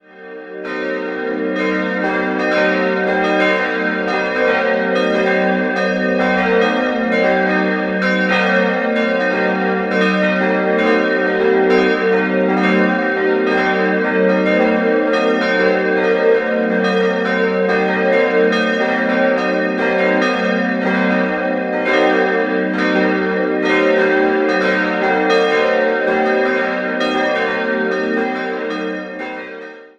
Idealquartett: g'-b'-c''-es'' Im Jahr 2014 wurden die vier neue Glocken geweiht, die in den Kunstwerkstätten der Abtei Maria Laach gegossen wurden.